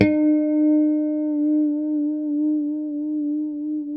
E4 PICKHRM2B.wav